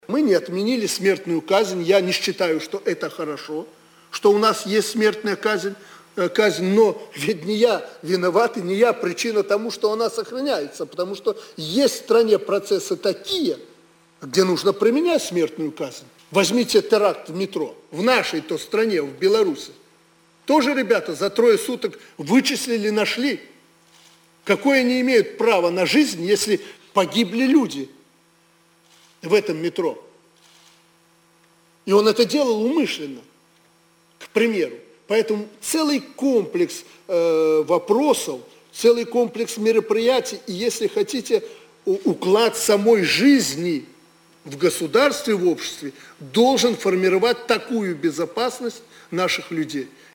Прэсавая канфэрэнцыя Аляксандра Лукашэнкі для расейскіх журналістаў. Менск, 11 кастрычніка 2013